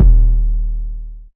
Bandz808_YC.wav